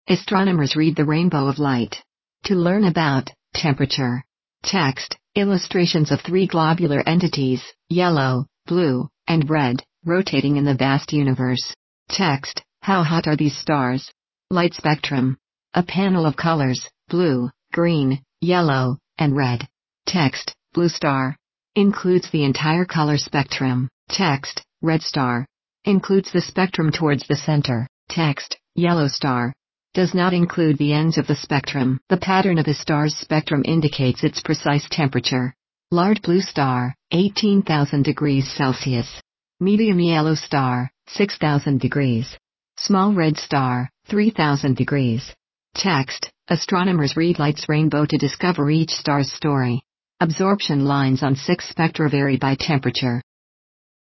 • Audio Description
Audio Description.mp3